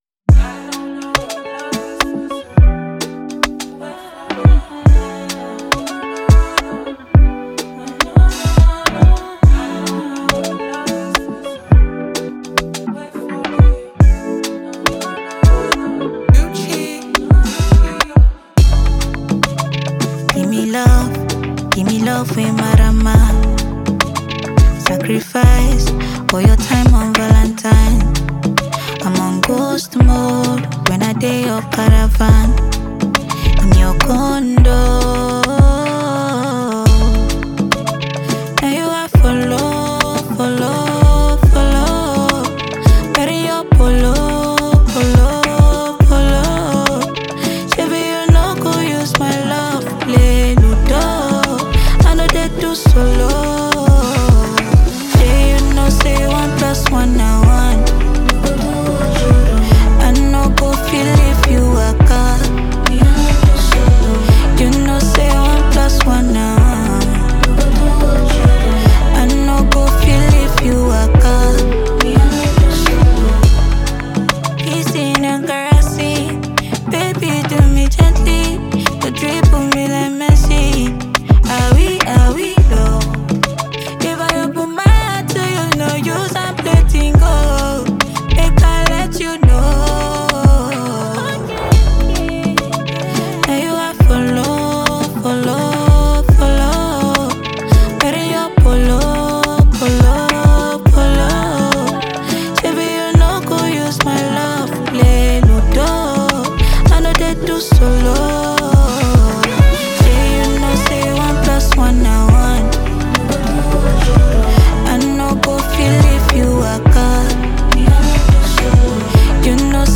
a sensational singer-songwriter from Nigeria